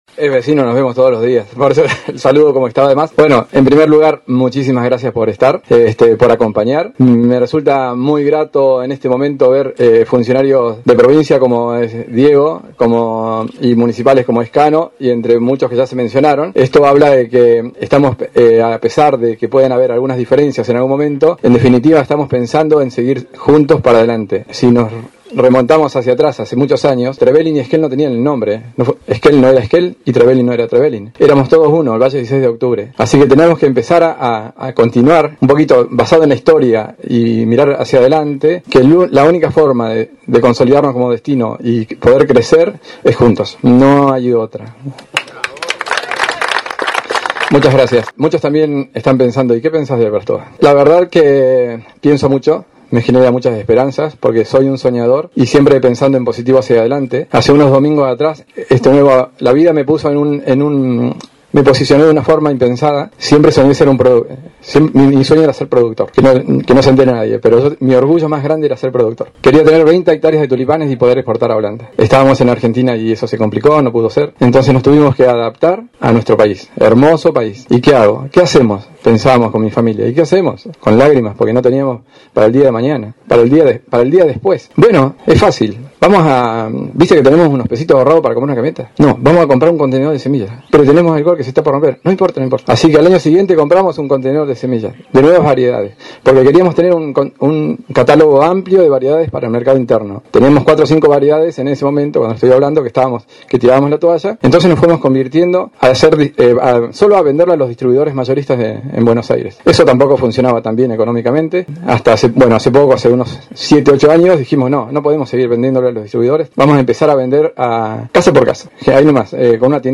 Al mediodía del lunes se hizo el acto de apertura de la temporada 2024 en el campo de tulipanes en Trevelin.